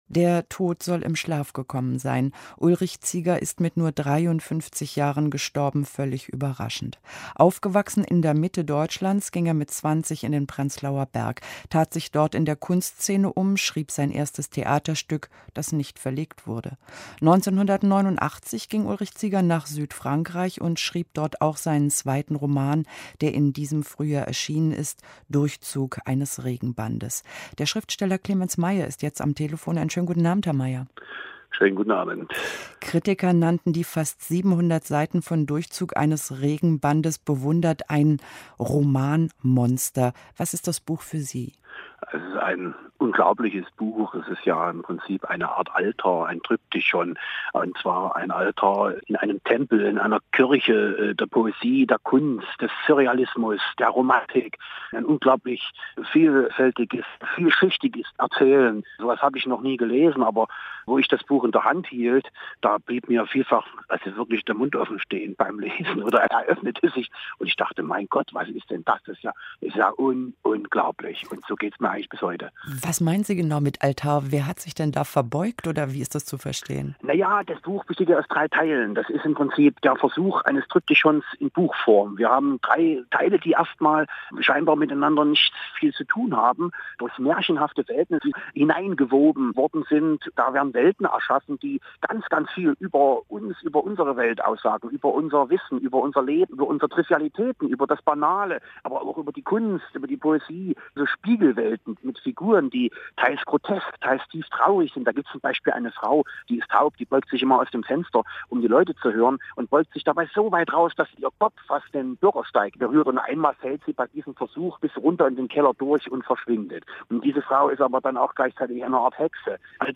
Clemens Meyer zum Tod von Ulrich Zieger. Moderation